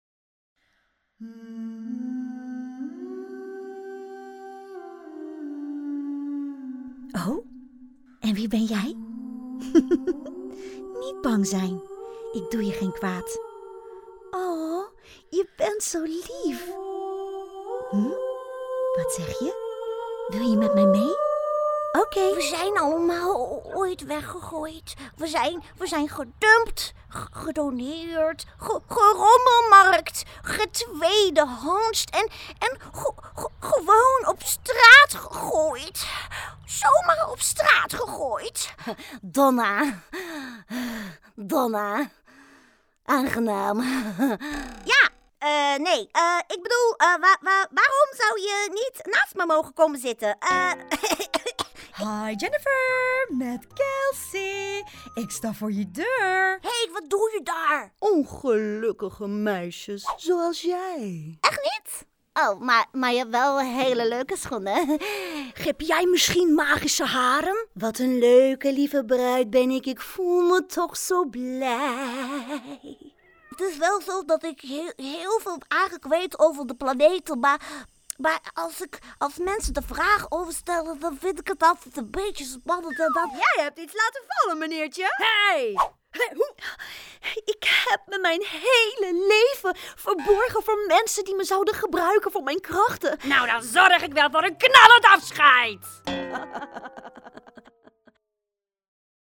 Distinctive, Playful, Versatile, Friendly, Warm